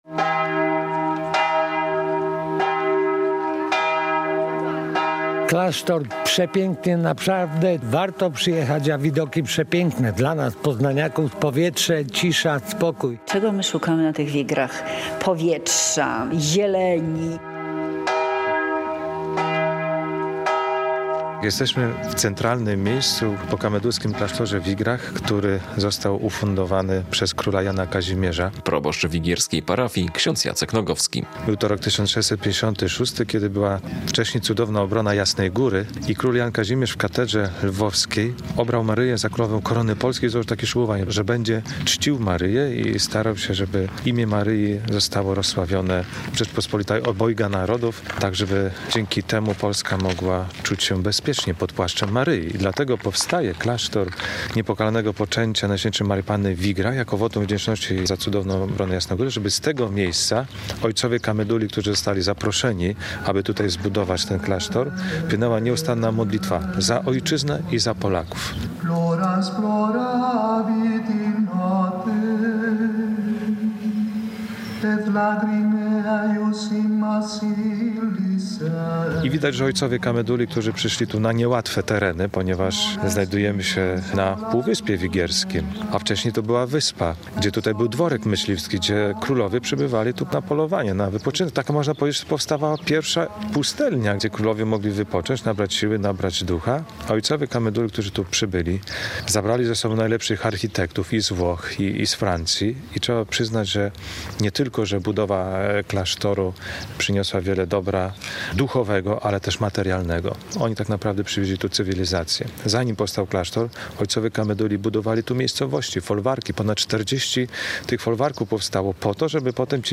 Odwiedzamy pokamedulski klasztor na Wigrach - relacja